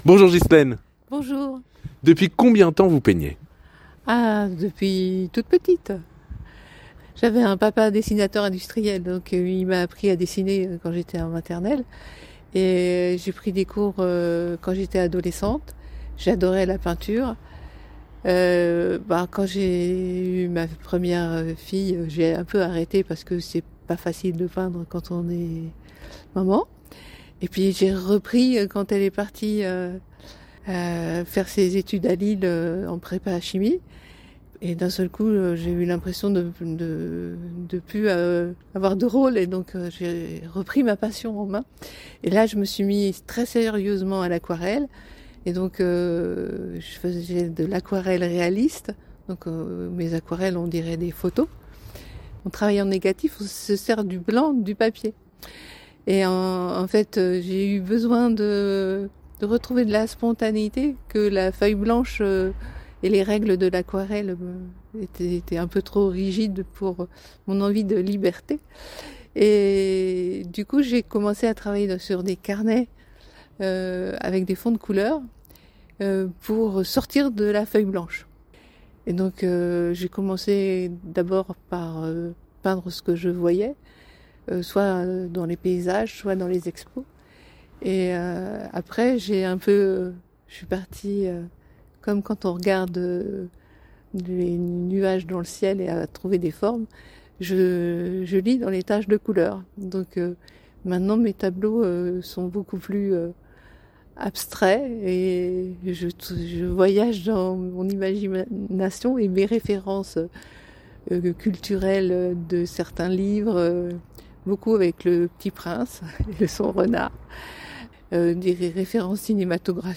Dans son atelier, où nous l’avons rencontrée vendredi dernier, les couleurs éclatent immédiatement. Sur les murs, les toiles composent un univers foisonnant, peuplé de personnages, de paysages oniriques et de scènes qui semblent toutes raconter une histoire.